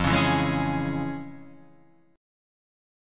FmM7#5 Chord